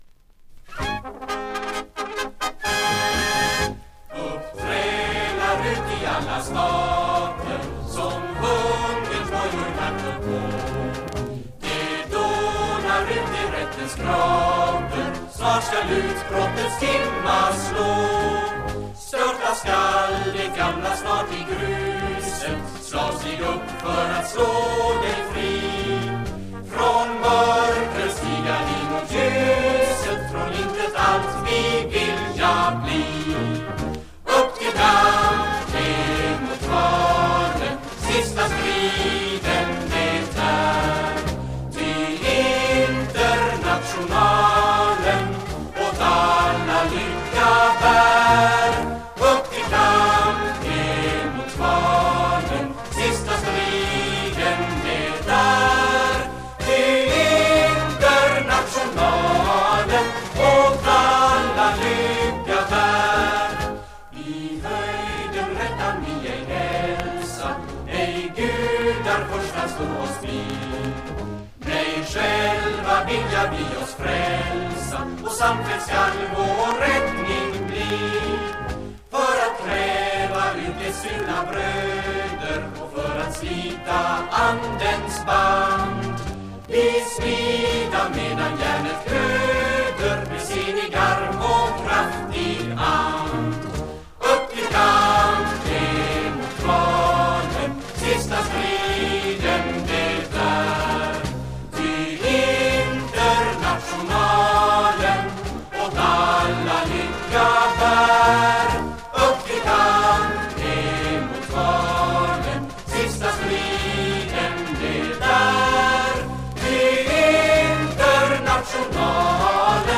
Progressiv rock!